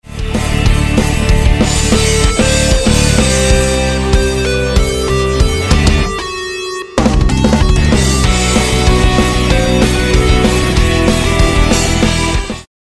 逆になんですけどじゃあその音入れないとどうなるんだい？って感じですよね。
なんかもの足りない感じがしませんか？